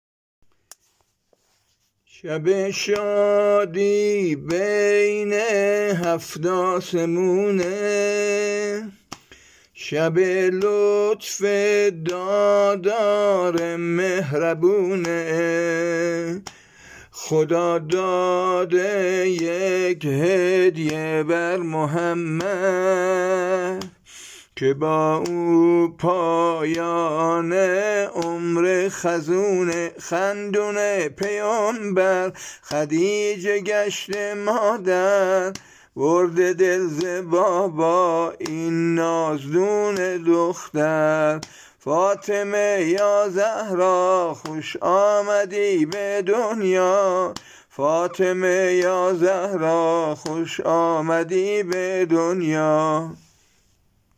سرود میلاد حضرت فاطمه(س)